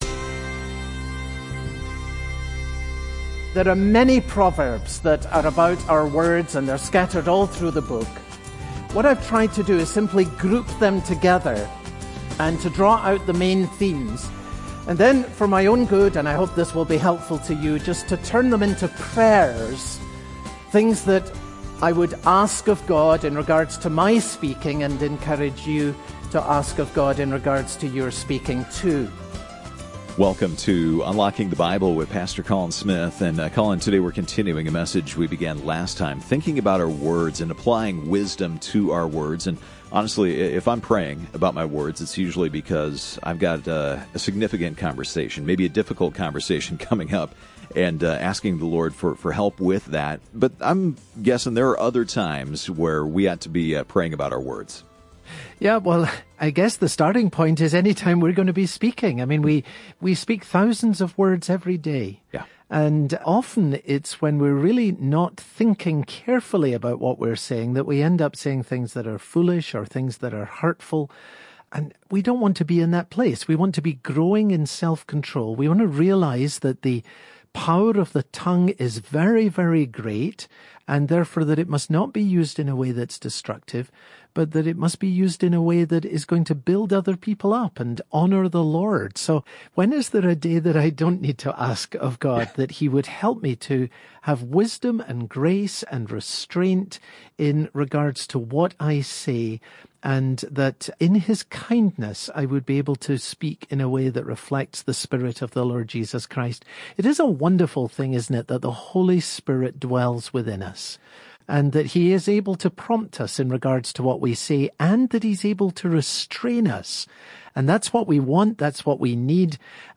Part 2 Proverbs Broadcast Details Date Sep 13